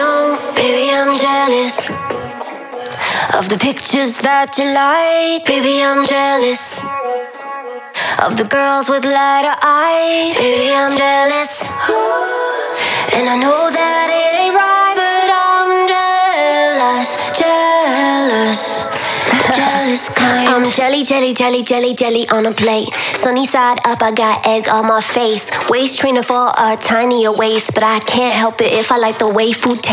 [A Cappella] Ringtone
• Pop